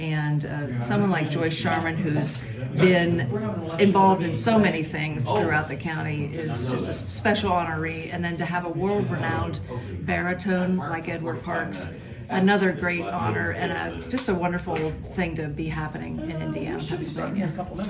Two Indiana natives were recognized with a proclamation at the Indiana County Commissioners meeting this morning.